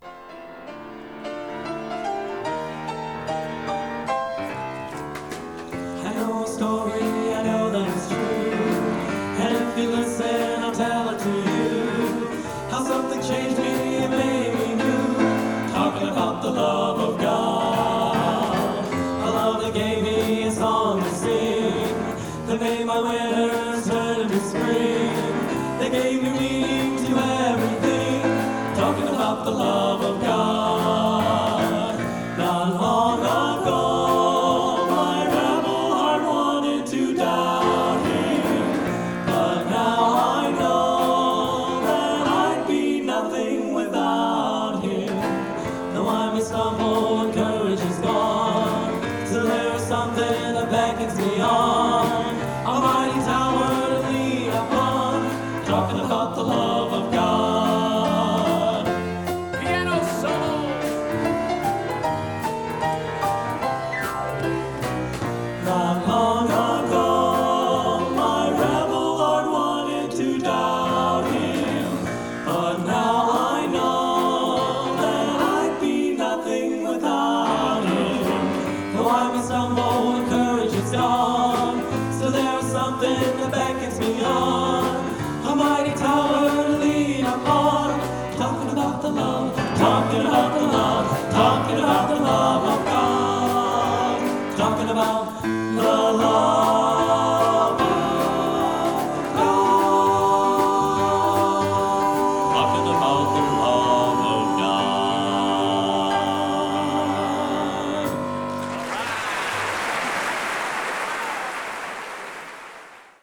Genre: Gospel | Type: Specialty